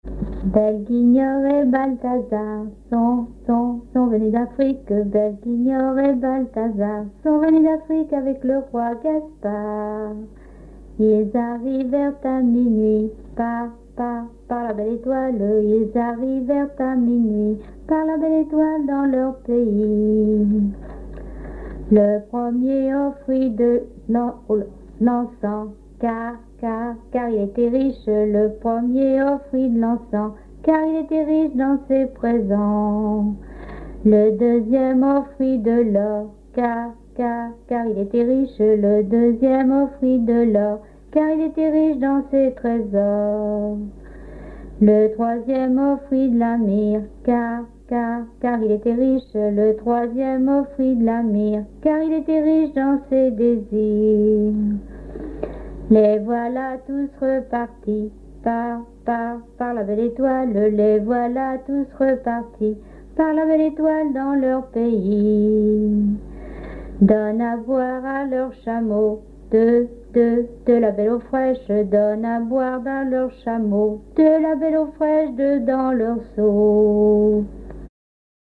danse : ronde
Pièce musicale inédite